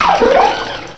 cry_not_keldeo.aif